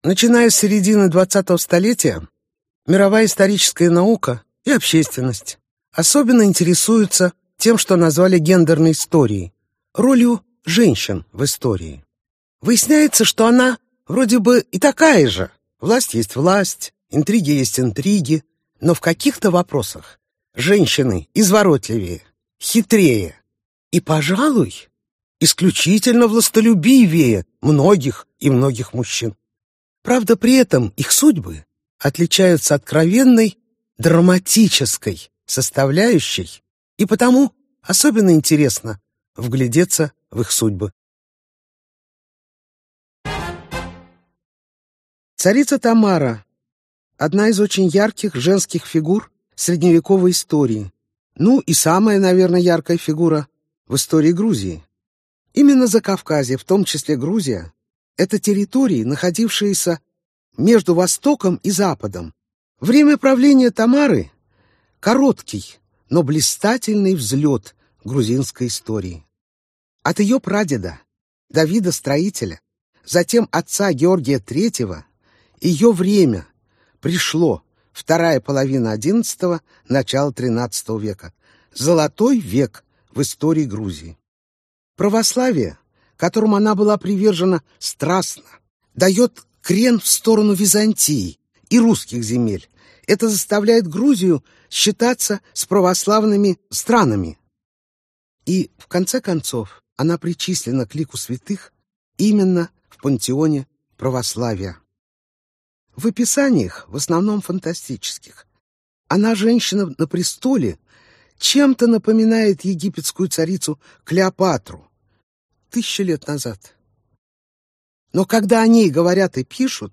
Aудиокнига Женщины в историческом контексте мировой истории Автор Наталия Басовская Читает аудиокнигу Наталия Басовская.